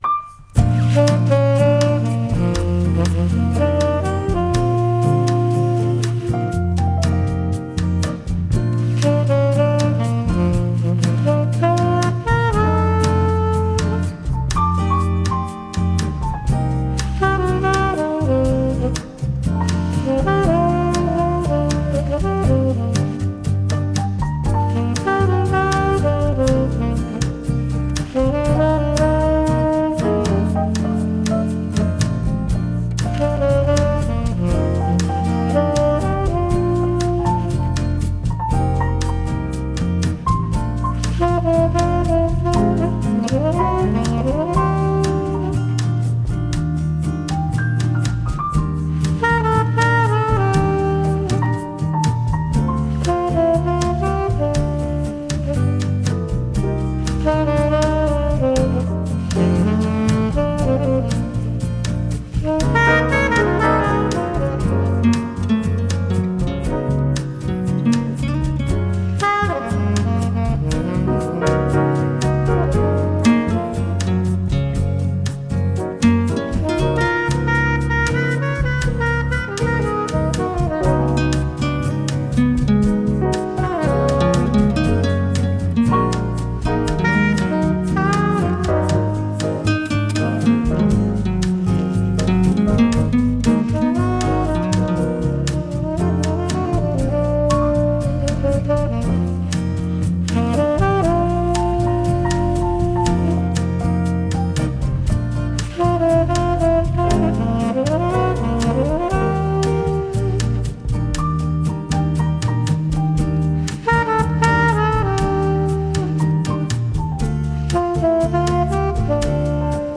Incidental Music